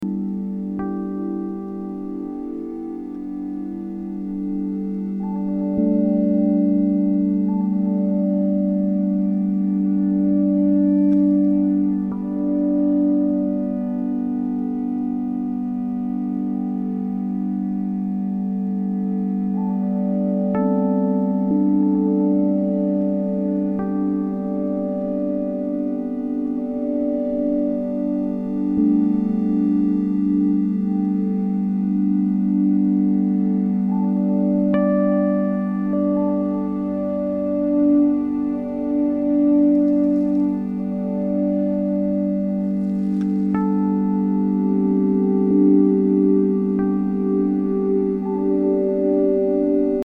Experimental >
Ambient, Drone >